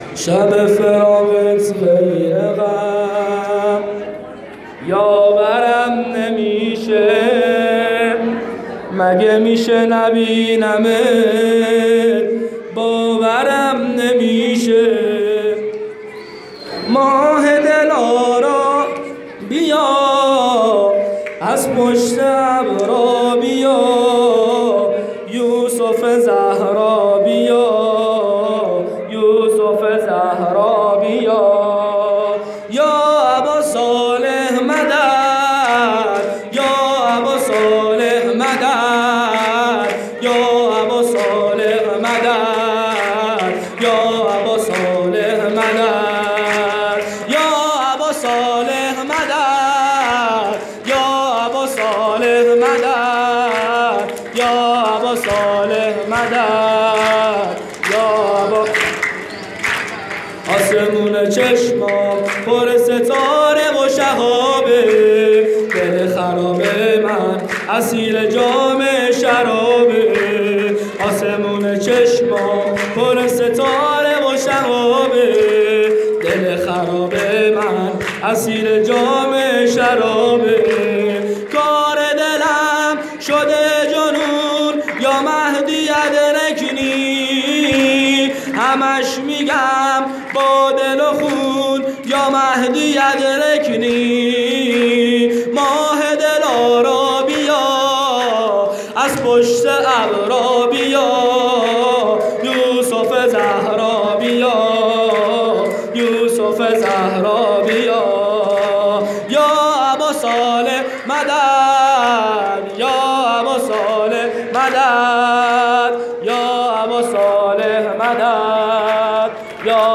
مراسم جشن نیمه شعبان۹۷